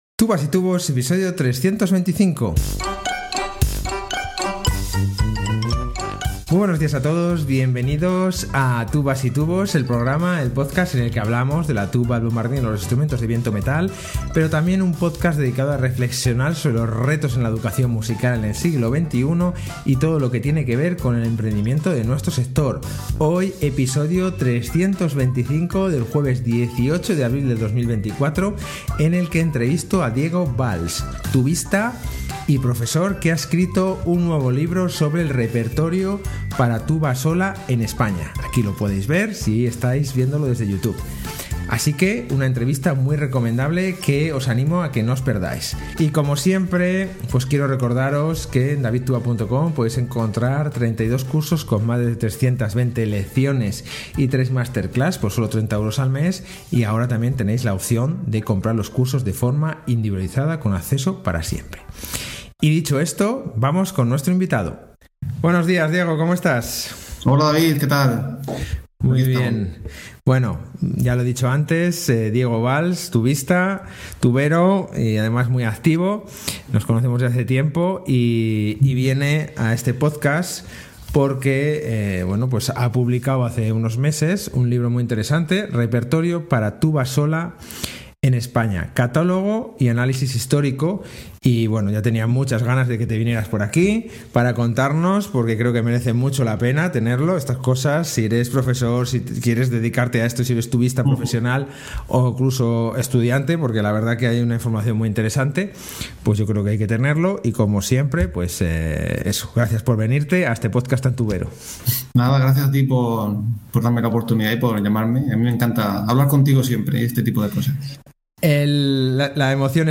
Hoy entrevisto